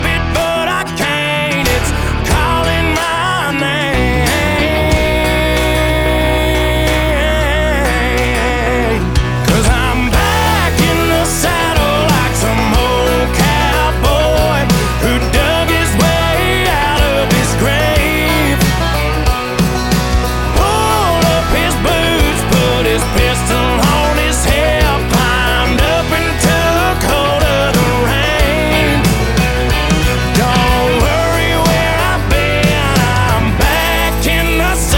2025-07-25 Жанр: Кантри Длительность